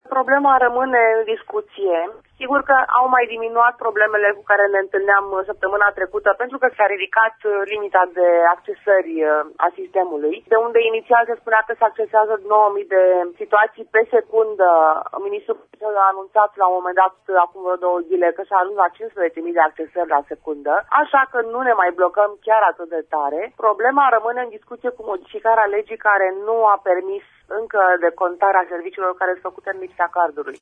a explicat în această dimineaţă la Radio România Actualităţi care este situaţia în prezent